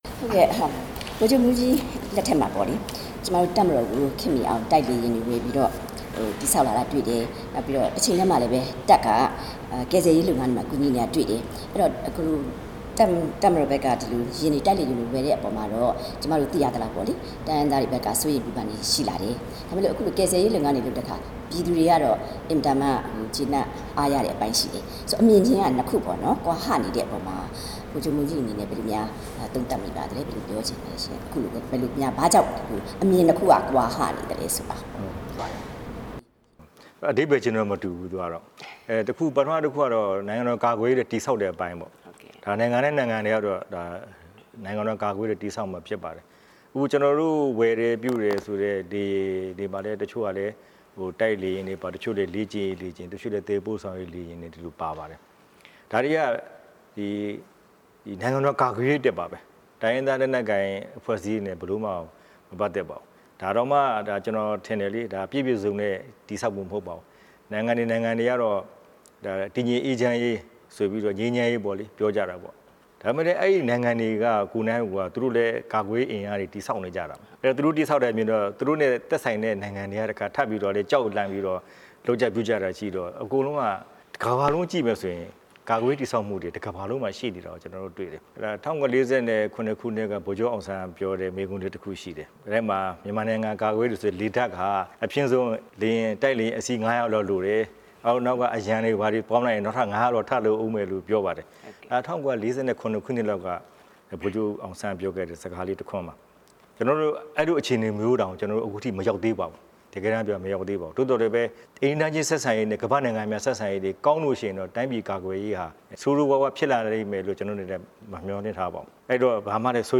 ဗိုလ်ချုပ်မှူးကြီး မင်းအောင်လှိုင်နဲ့ RFA သီးသန့်တွေ့ဆုံမေးမြန်းချက် (ပထမပိုင်း)